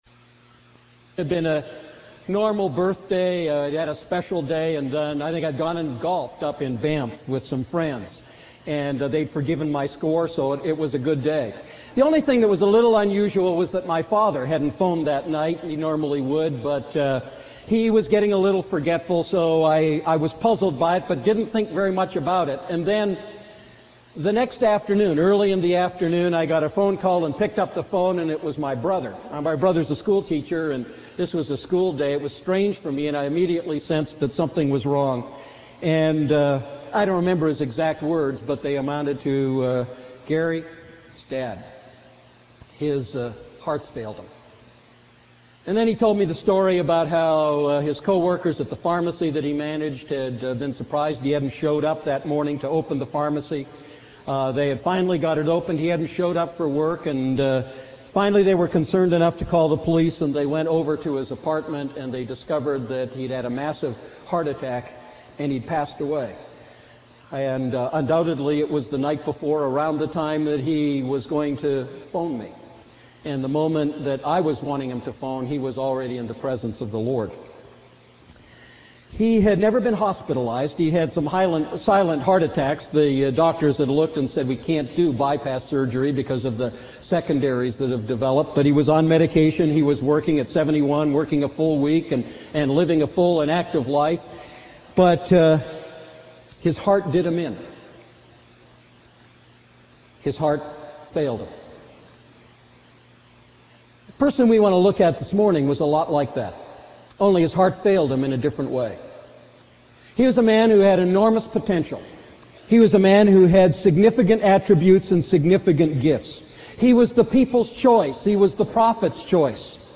A message from the series "Living Inside Out."